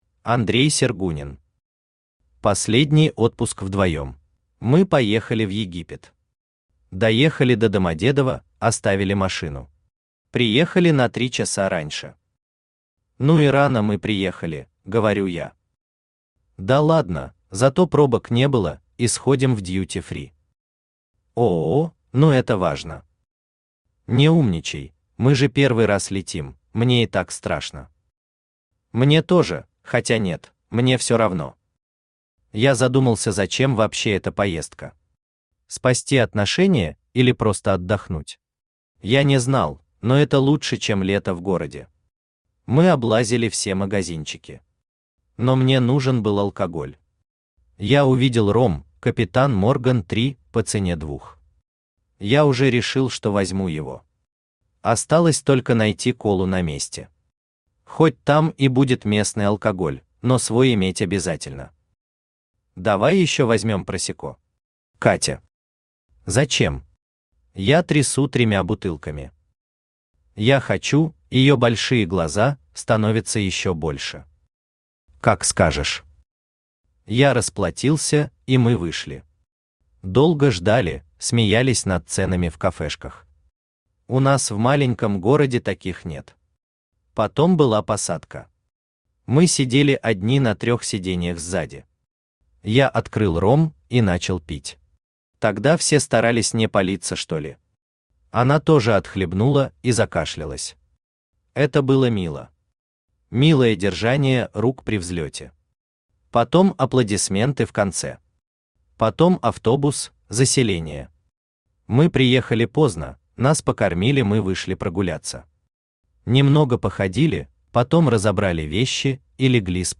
Аудиокнига Последний отпуск вдвоем | Библиотека аудиокниг
Aудиокнига Последний отпуск вдвоем Автор Андрей Андреевич Сергунин Читает аудиокнигу Авточтец ЛитРес.